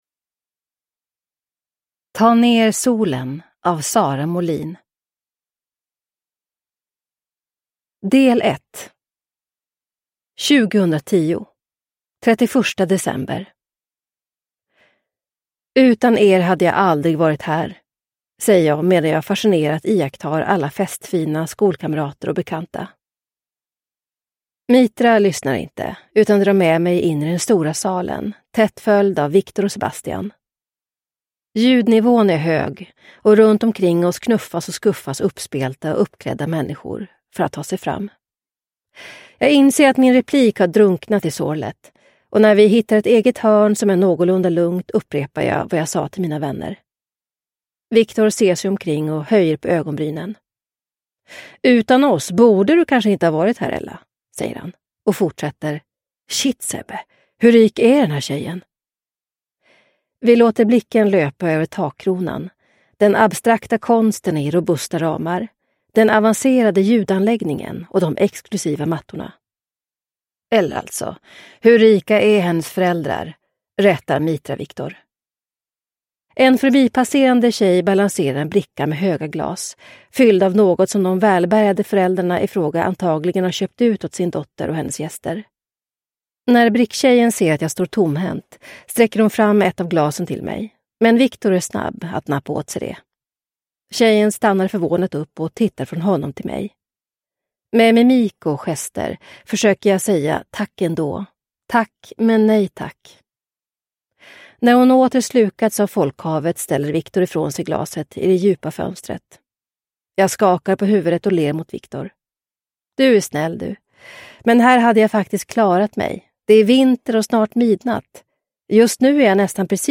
Ta ner solen – Ljudbok – Laddas ner